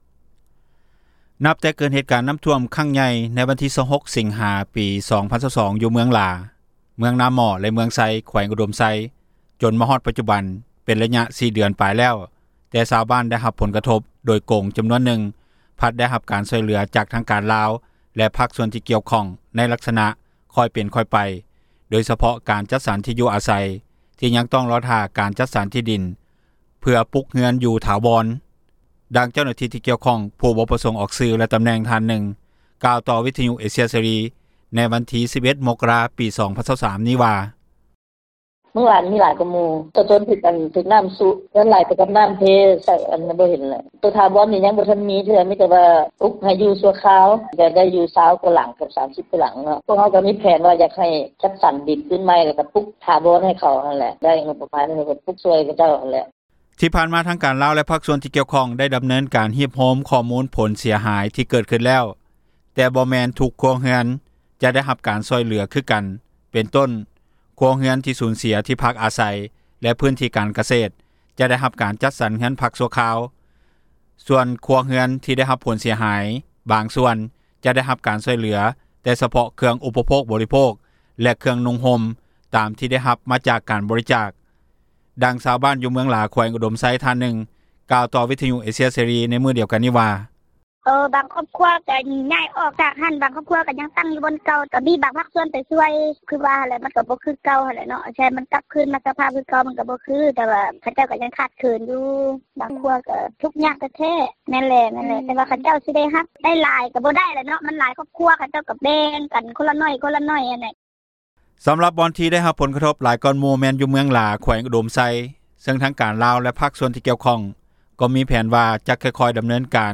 ດັ່ງຊາວບ້ານ ຢູ່ເມືອງຫລາ ແຂວງອຸດົມໄຊ ທີ່ໄດ້ຮັບຜົນກະທົບ ໂດຍຕຣົງທ່ານນຶ່ງກ່າວວ່າ:
ດັ່ງຊາວບ້ານ ຢູ່ບ້ານໃກ້ຄຽງຜູ້ຖືກຜົນກະທົບ ທ່ານນຶ່ງກ່າວວ່າ: